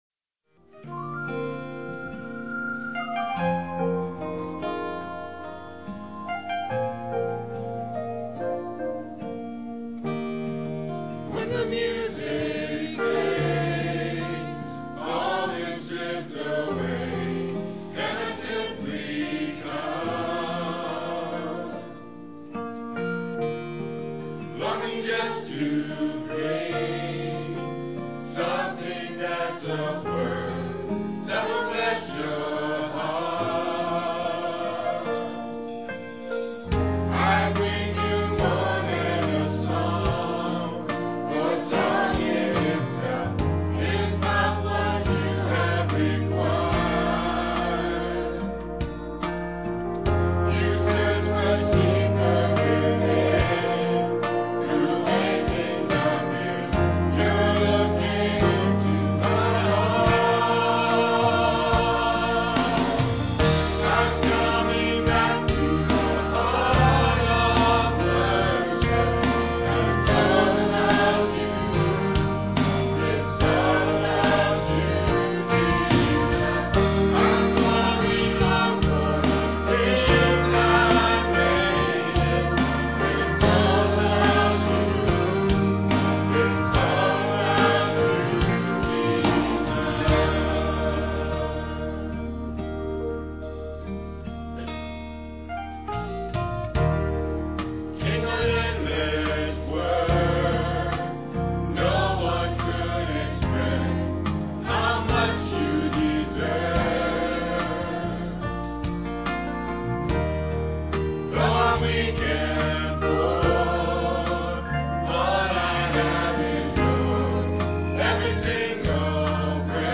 solo
Piano and organ duet